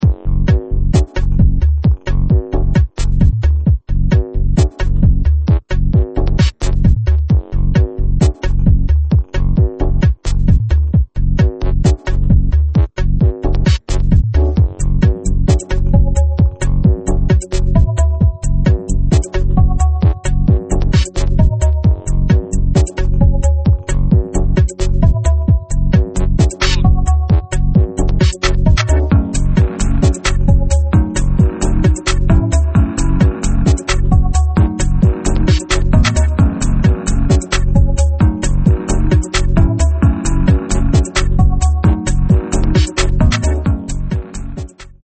2005 nervous fast instr.